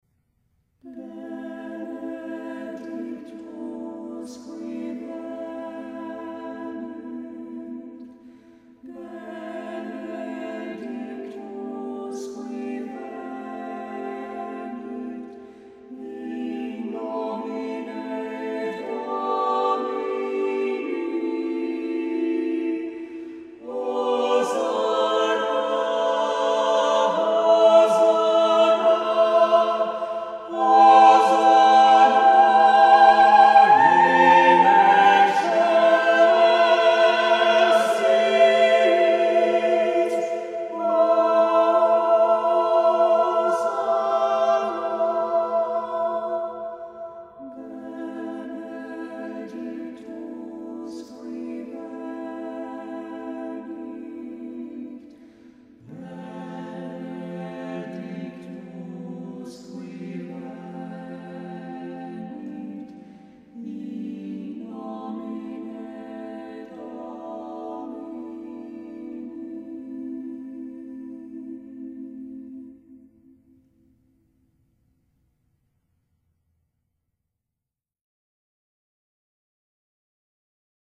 Choral Music
• SATB